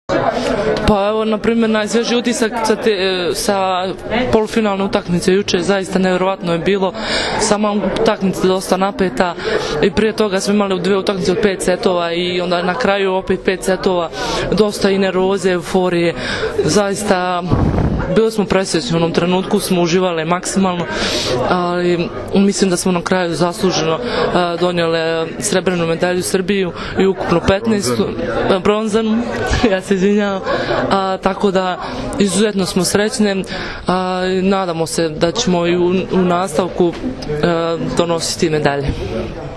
IZJAVA BRANKICE MIHAJLOVIĆ